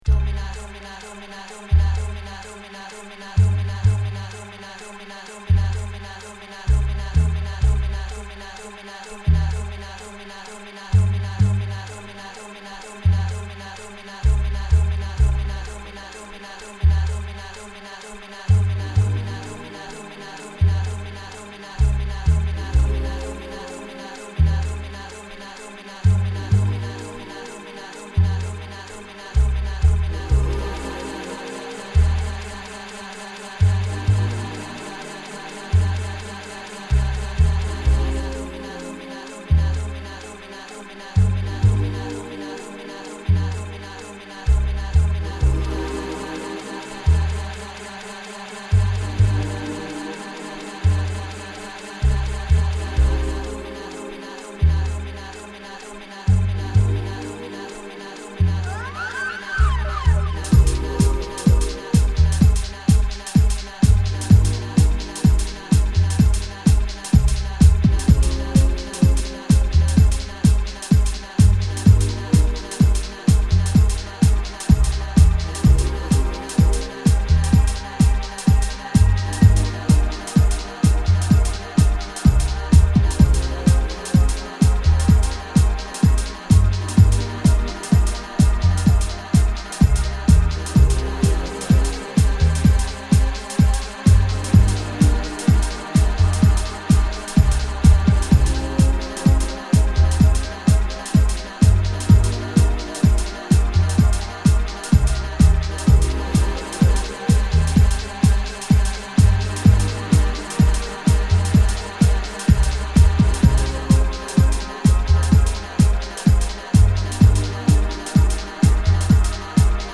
Remastered